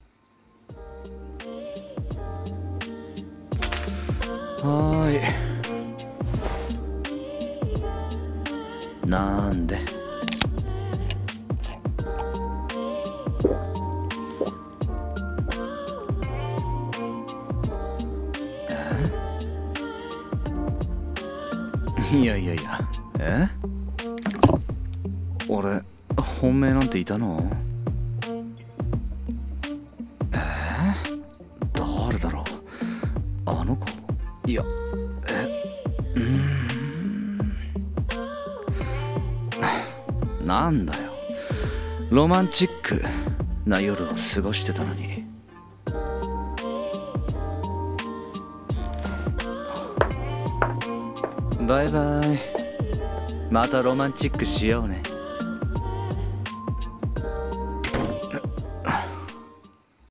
クズ的ロマンチック論 【2人用 台本 声劇】